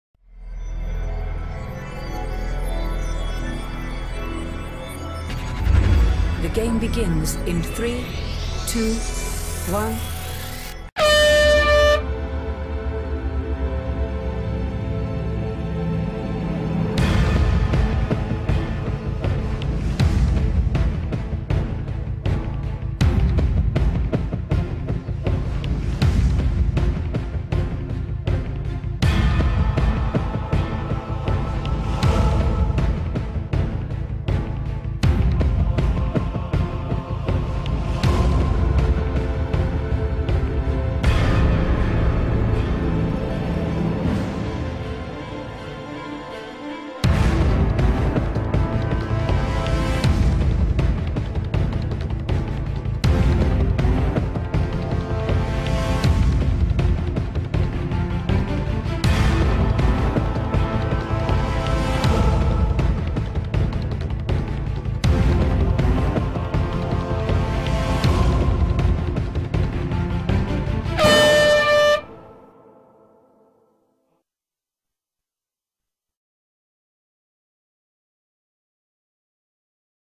跳繩比賽音樂檔